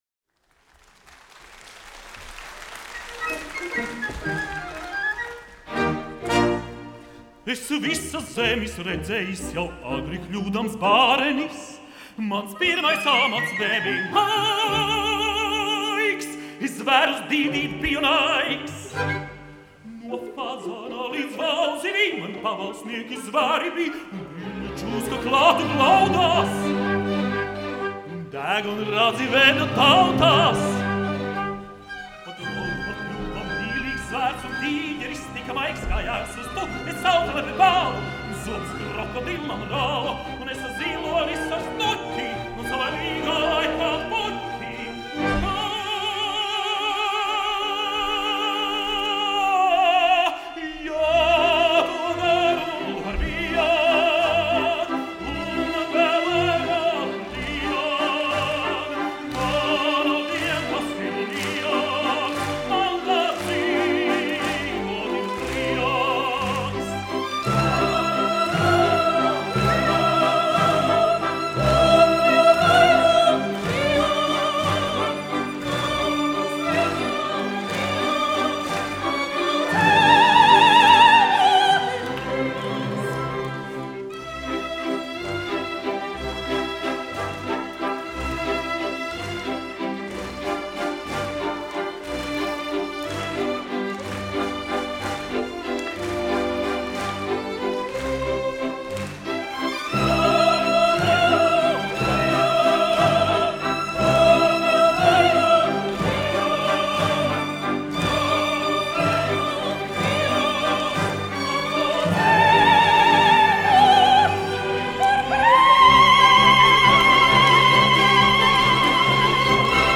LNSO orķestris, izpildītājs
Kuplejas
Aplausi !
Jautrs
Mūzika no operetes
Siguldas estrāde